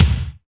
GATEKICKR.wav